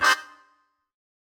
GS_MuteHorn-Cmin9.wav